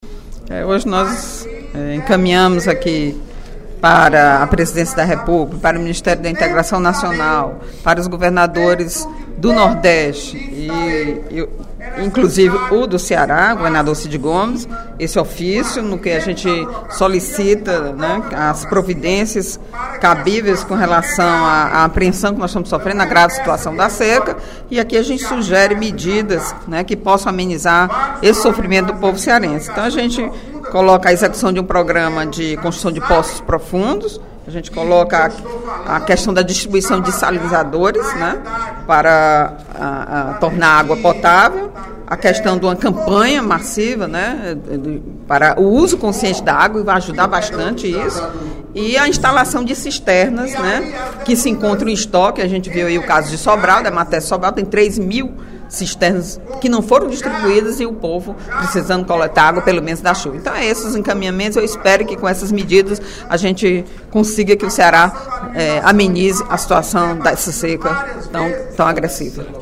Em pronunciamento durante o primeiro expediente da sessão plenária desta terça-feira (02/04), a deputada Eliane Novais (PSB) comunicou o encaminhamento de ofício, de sua autoria, à Presidência da República e ao Ministério da Integração Nacional, solicitando providências para a problemática da seca.